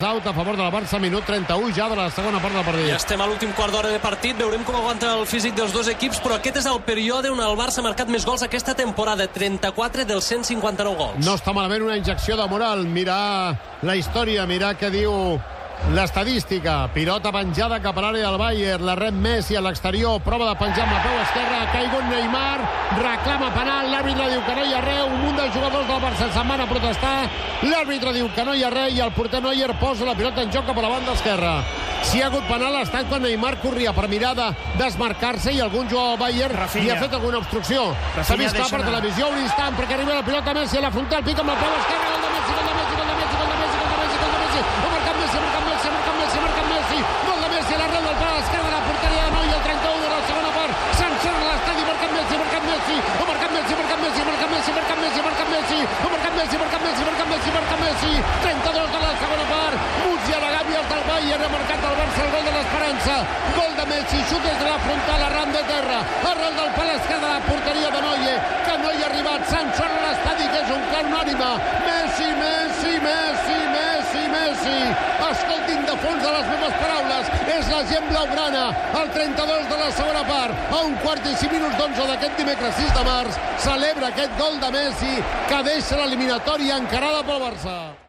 Transmissió del partit de la fase eliminatòria de la Copa d'Europa de futbol masculí entre el Futbol Club Barcelona i el Bayern München.
Minut 31 de la segona part. Narració d'una jugada i del primer gol de Leo Messi. El públic canta el seu nom.
Esportiu